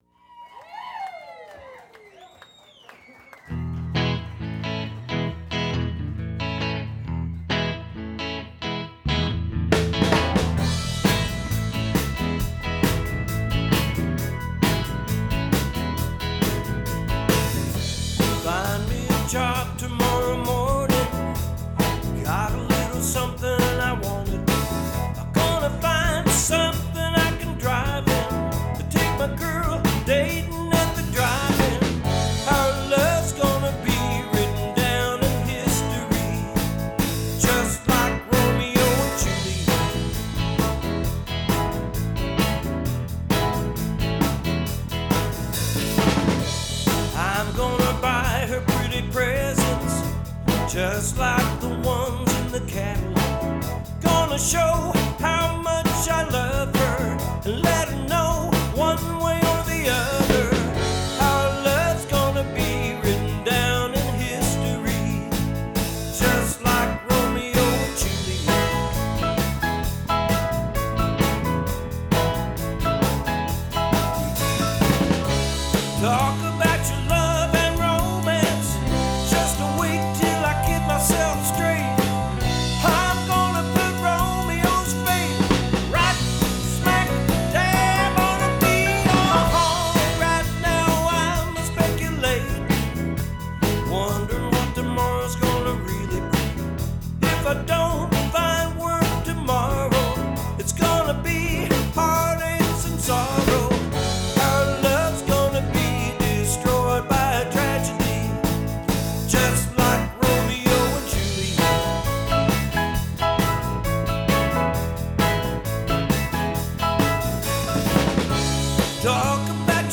guitar, vocal
bass, vocal
drums
piano